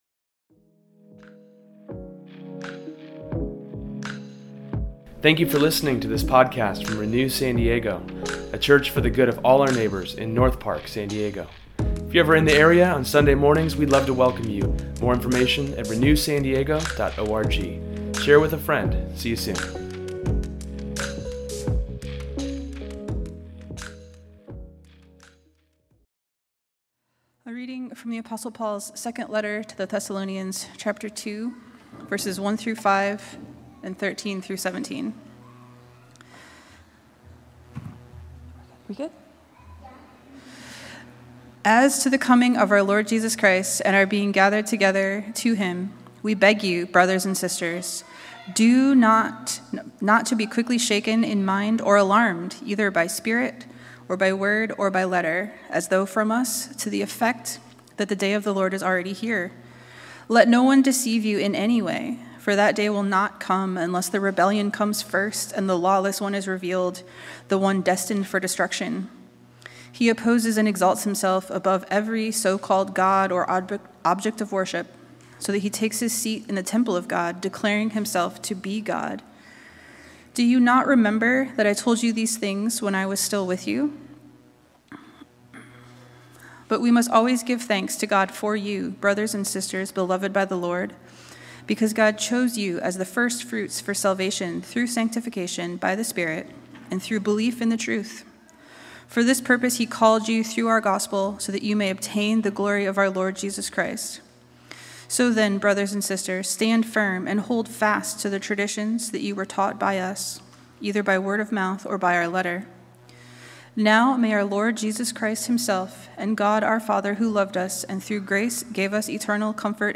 Today’s sermon explores how to not be deceived by culture, and to be aware of society when it tries to put itself over God.